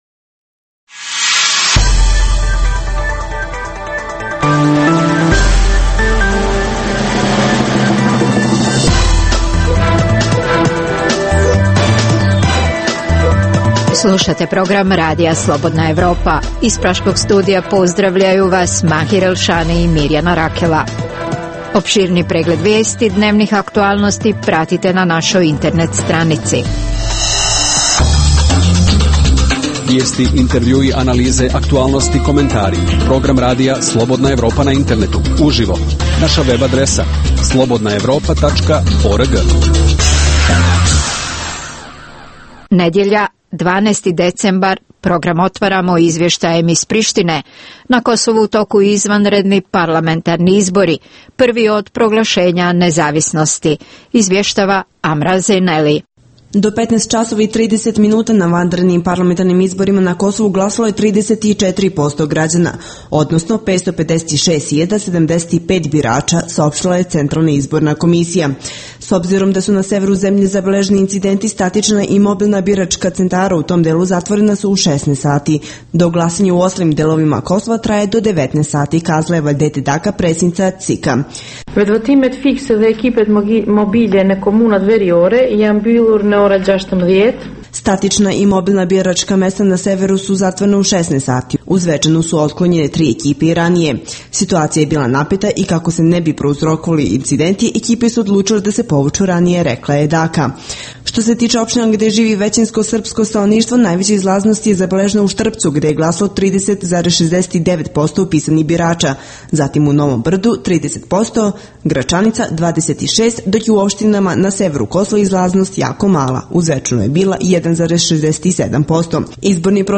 u kojem ugledni sagovornici iz regiona diskutuju o aktuelnim temama.